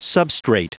Prononciation du mot substrate en anglais (fichier audio)
Prononciation du mot : substrate